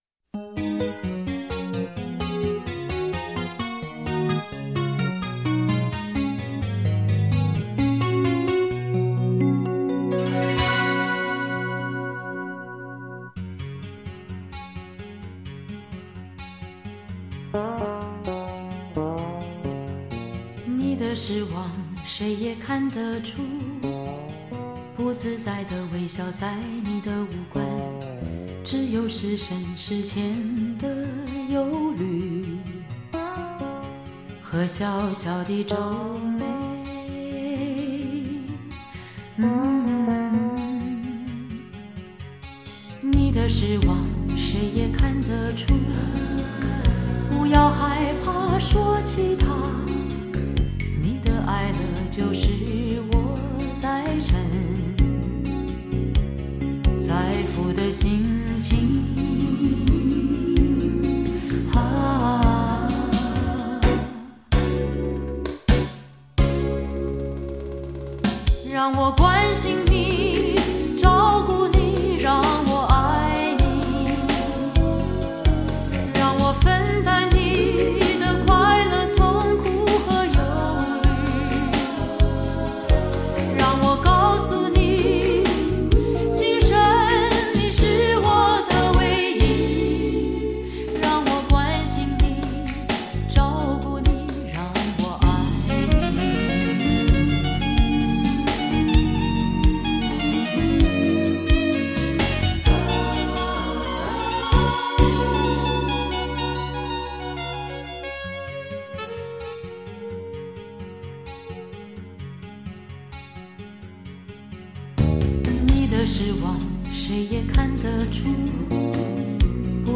她的歌沒有激盪的豪情，更缺乏惹人興奮的節奏，而是帶有濃重的懷舊 感。